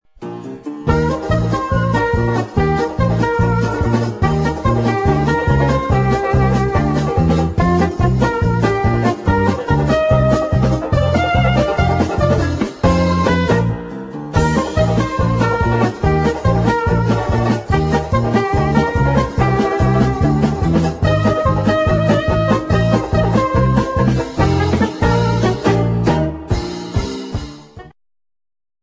funny fast instr.